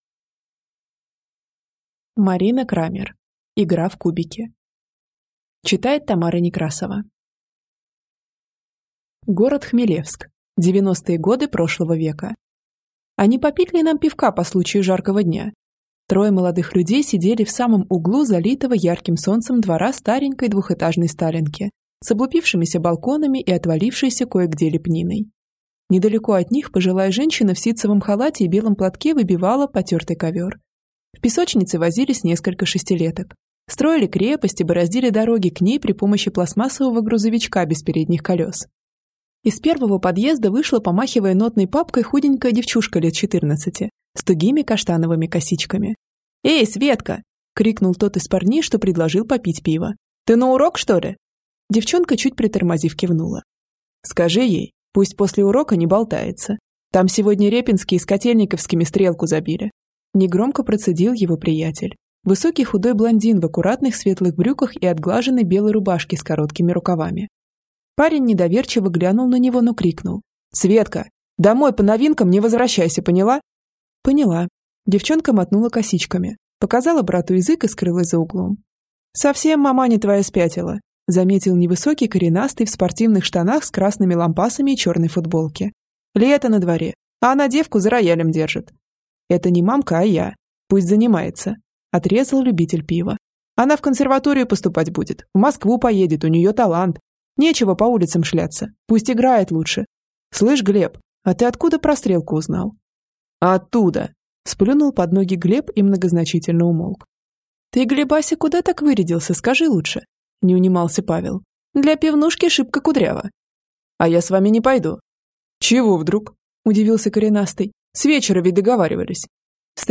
Аудиокнига Игра в кубики | Библиотека аудиокниг